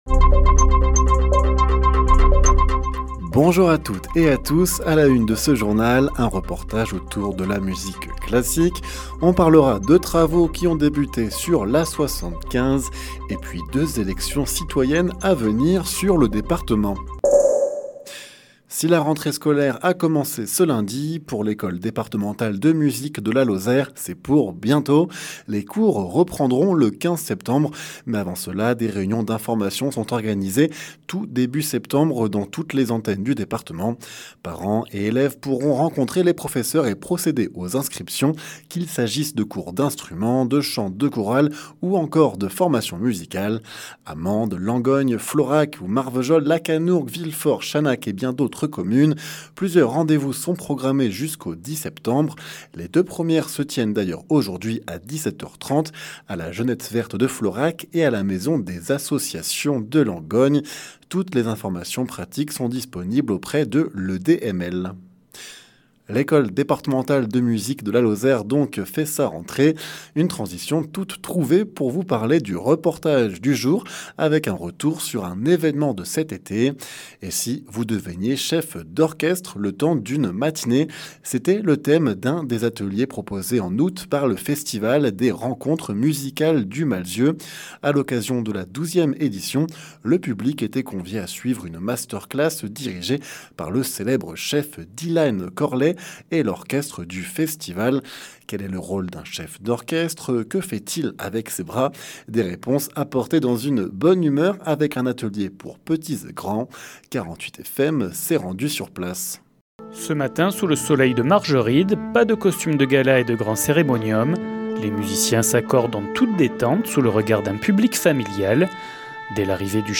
Les informations locales
Le journal sur 48FM